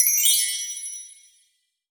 chimes_magical_bells_06.wav